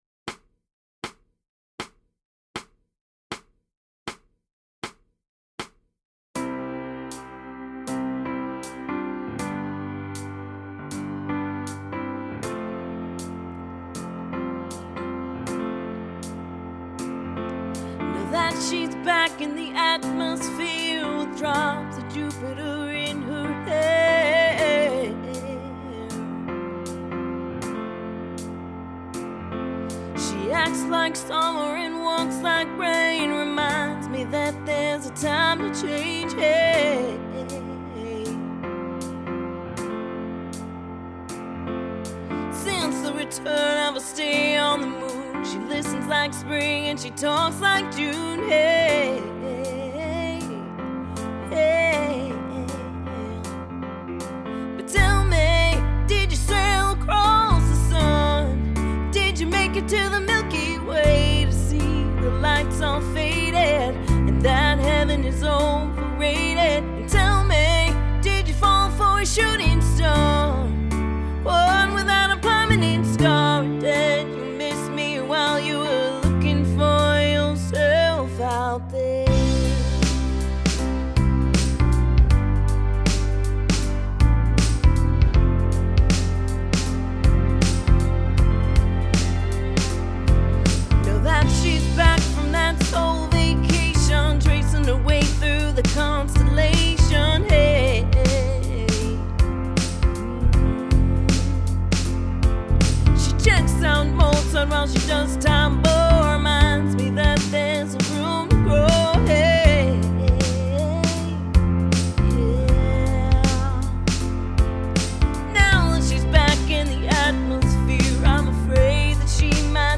Jam Track No Vocals